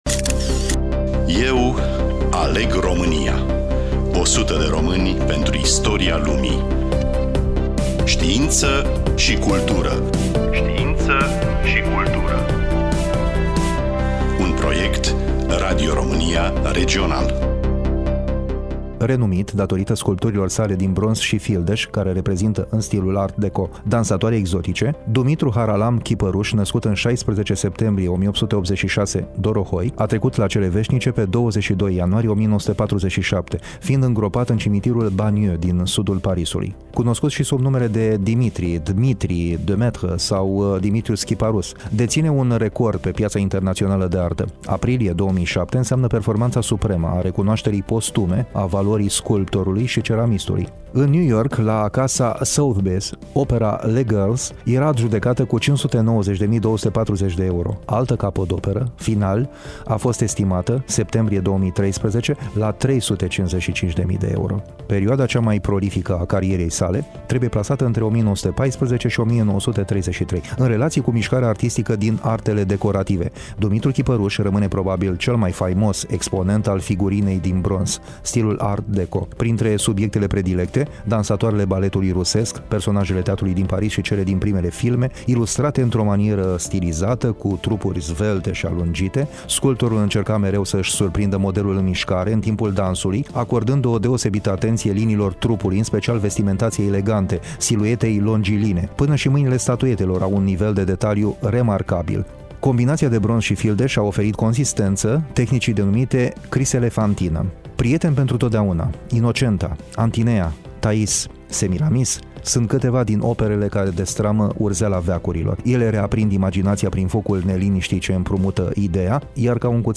Studioul: RADIO ROMÂNIA IAȘI